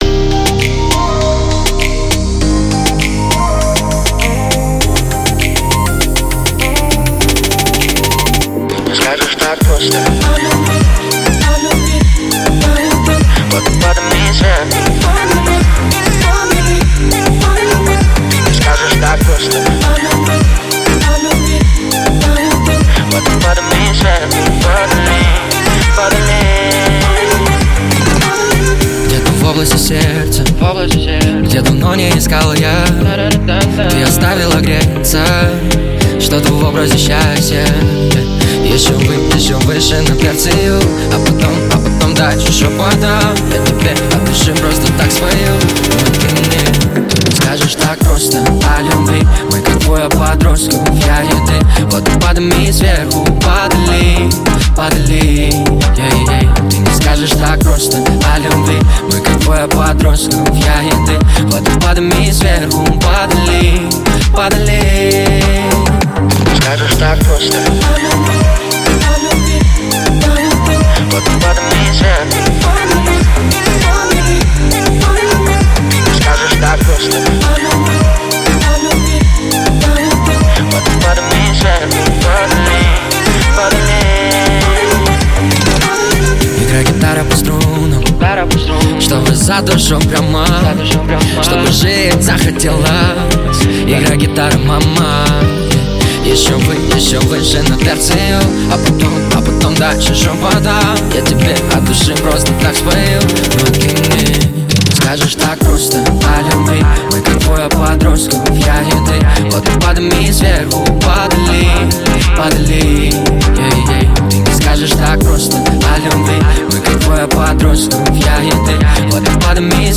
Жанр: Жанры / Электроника